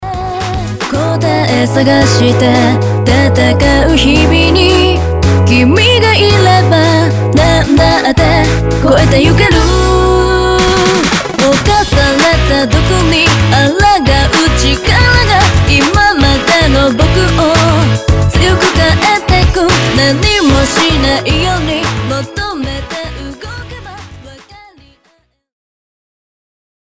HOUSE　J-POP 　オリジナル